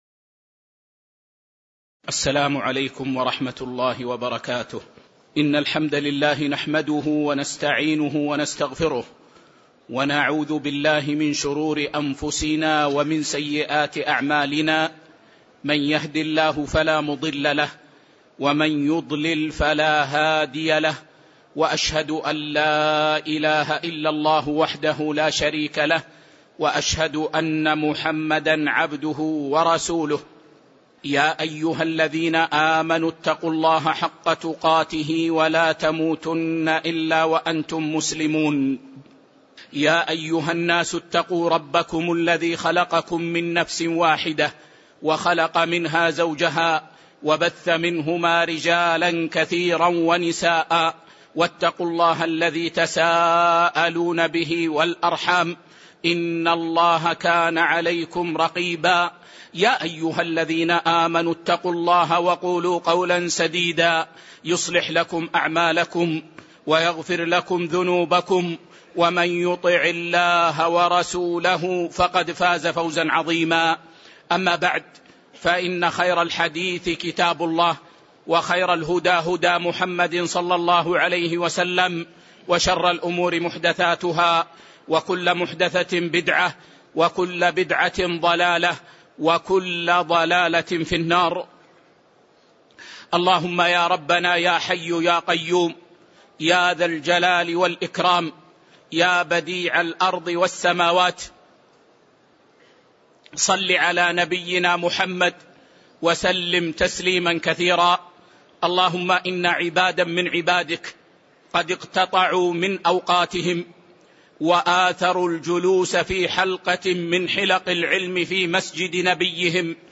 تاريخ النشر ١١ ربيع الأول ١٤٤١ هـ المكان: المسجد النبوي الشيخ